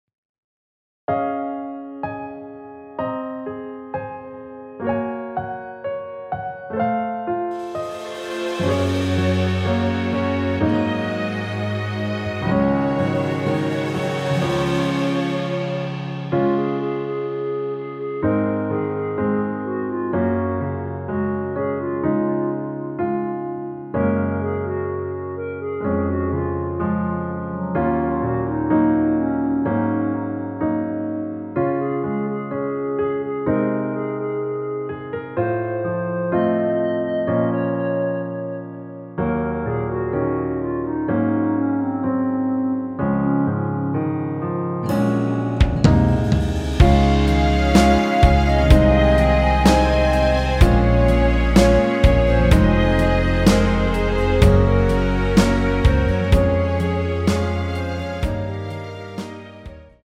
원키? (1절앞+후렴)으로 진행되는 멜로디 포함된 MR입니다.(미리듣기 확인)
Db
앞부분30초, 뒷부분30초씩 편집해서 올려 드리고 있습니다.
중간에 음이 끈어지고 다시 나오는 이유는